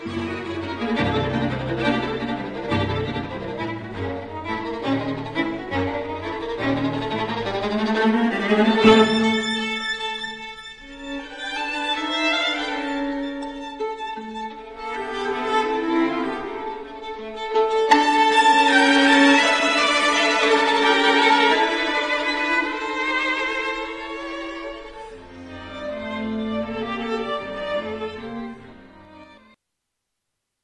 s-q-fmaj-2.mp3